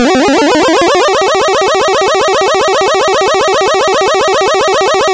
buster-charge-nes.mp3